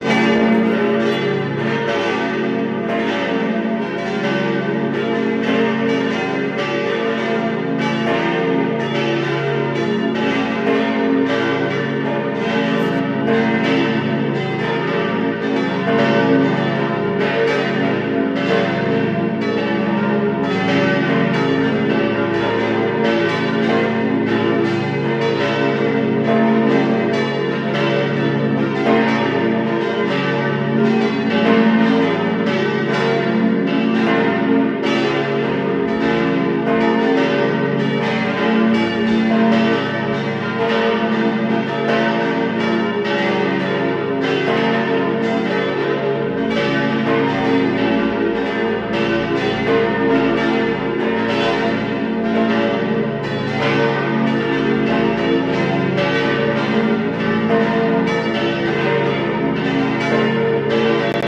Unsere fünf Glocken stammen aus dem Jahr 1954. Das Vollgeläut tönt so:
Vollgeläut